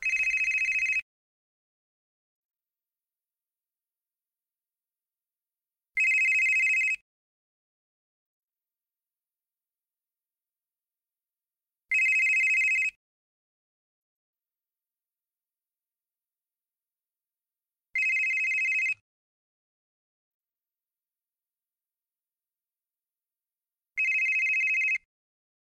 mobile-phone-ringtones